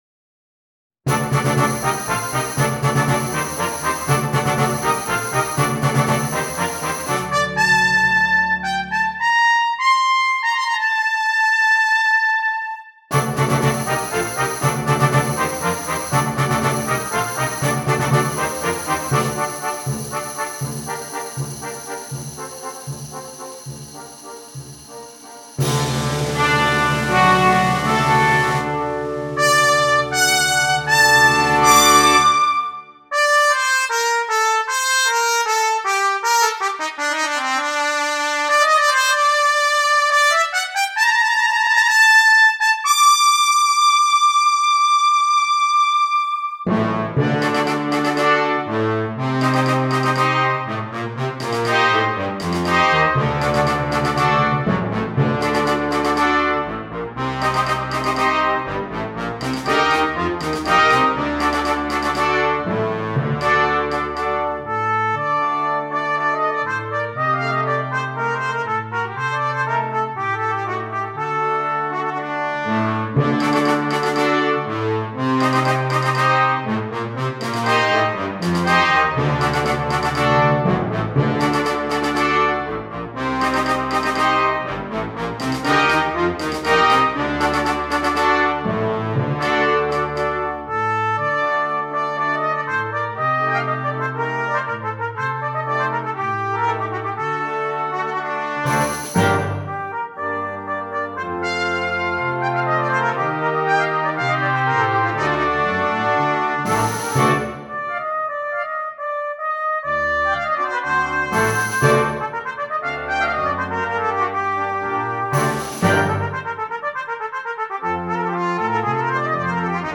Brass Band
Traditional Spanish